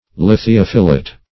Search Result for " lithiophilite" : The Collaborative International Dictionary of English v.0.48: Lithiophilite \Lith`i*oph"i*lite\ (l[i^]th`[i^]*[o^]f"[i^]*l[imac]t), n. [Lithium + Gr. fi`los friend.]